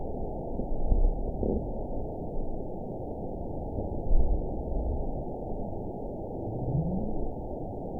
event 917095 date 03/19/23 time 23:15:29 GMT (2 years, 1 month ago) score 9.61 location TSS-AB04 detected by nrw target species NRW annotations +NRW Spectrogram: Frequency (kHz) vs. Time (s) audio not available .wav